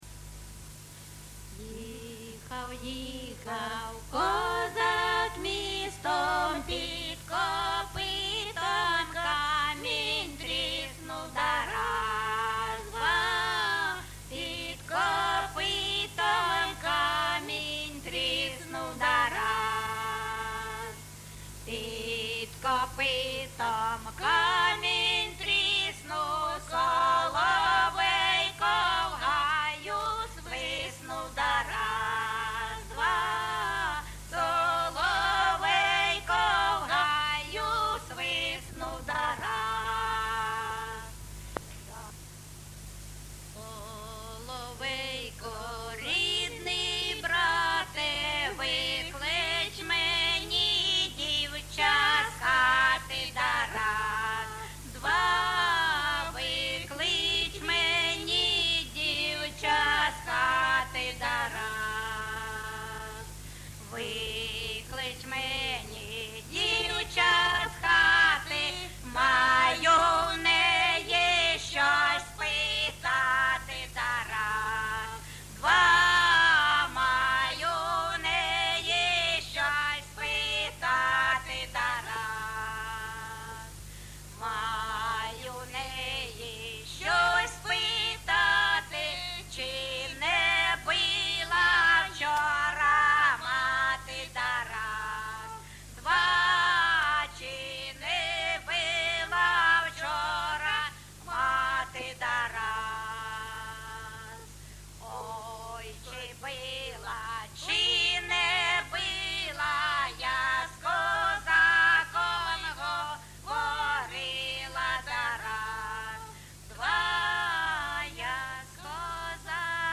ЖанрКозацькі
Місце записус-ще Новодонецьке, Краматорський район, Донецька обл., Україна, Слобожанщина